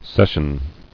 [ces·sion]